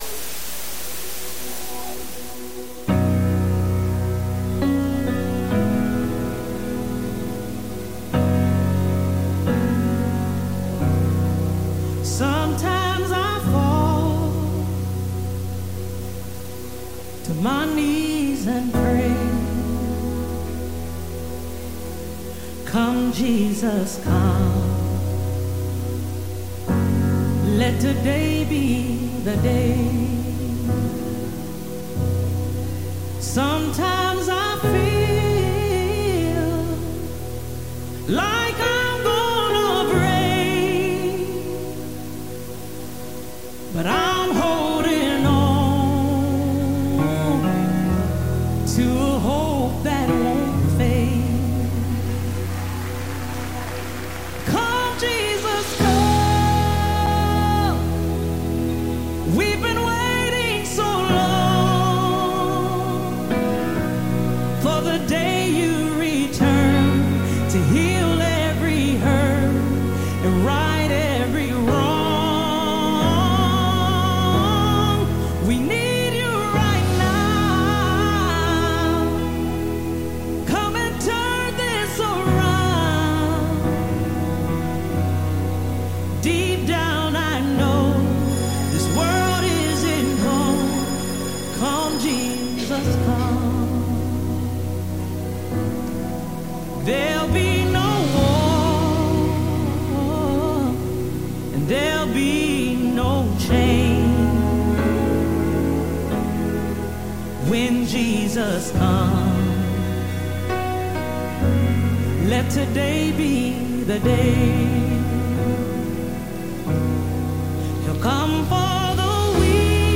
Sunday Morning Teaching